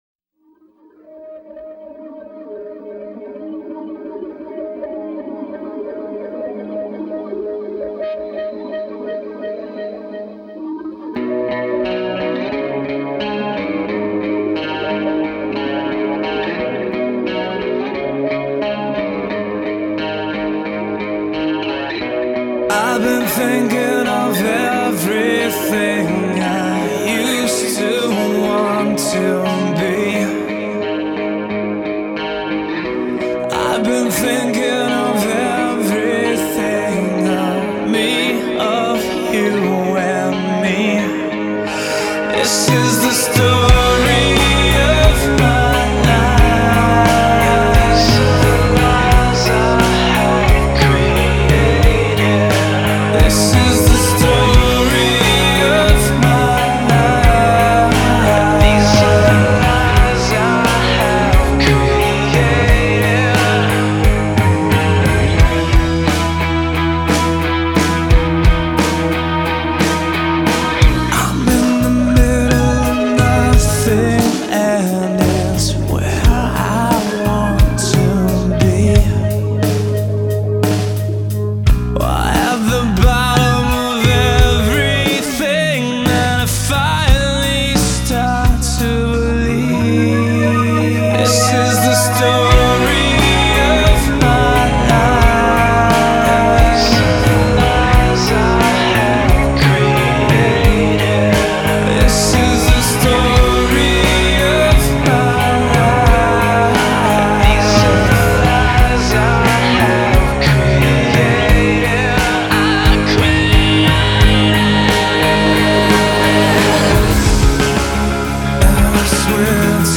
Genre: Alternative Rock